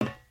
sounds / material / human / step / metal_plate4.ogg
metal_plate4.ogg